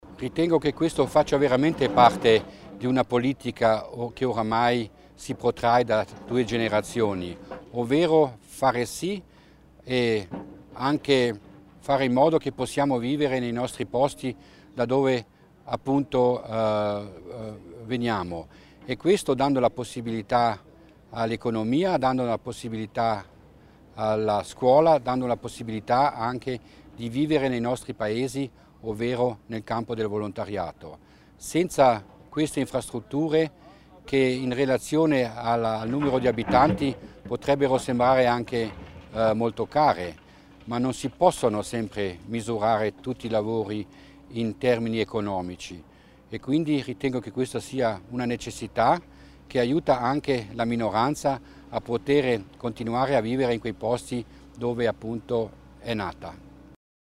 L'Assessore Mussner spiega l'importanza degli interventi anche in località periferiche